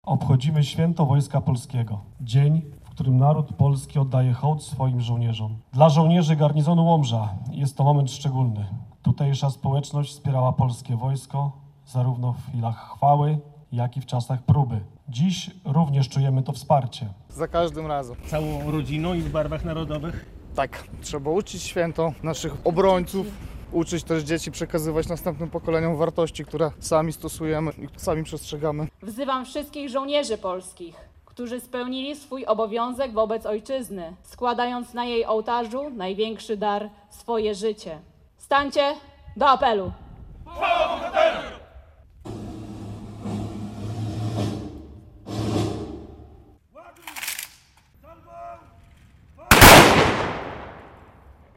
Uroczystości z okazji święta Wojska Polskiego w Łomży - relacja